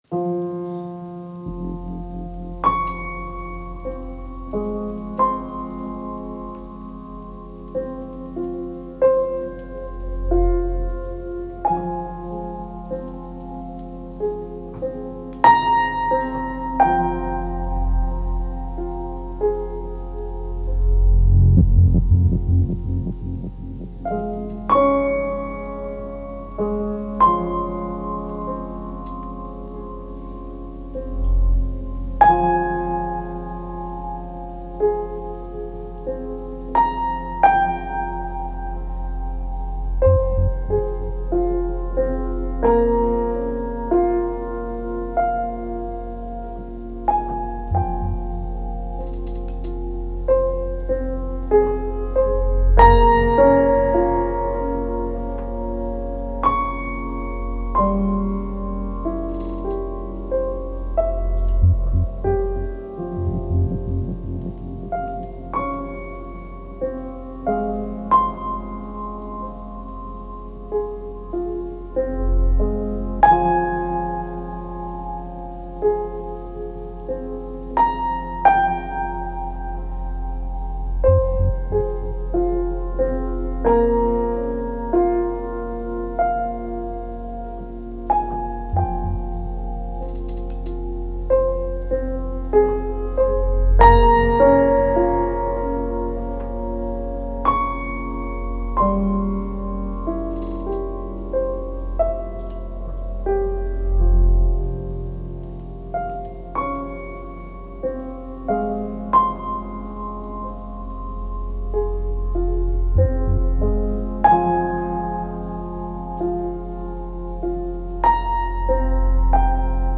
Melancholy slow film score with piano.